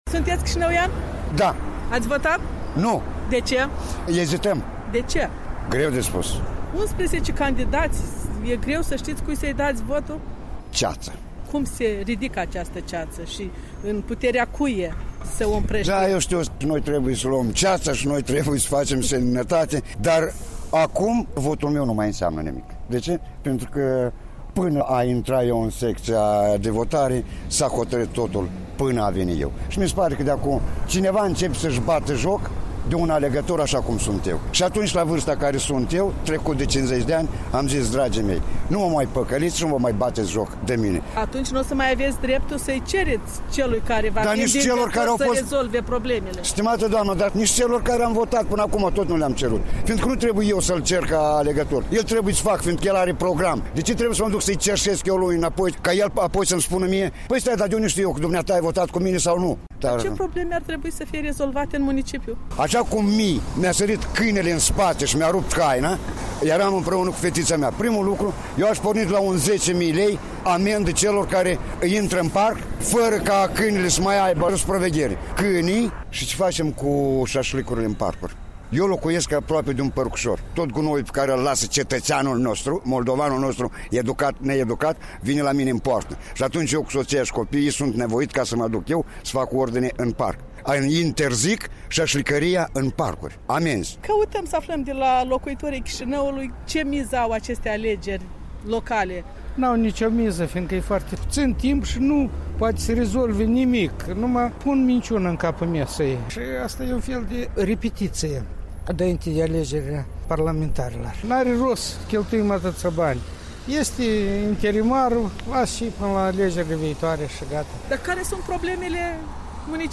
Voci de alegători din Chișinău adunate la Radio Europa Liberă.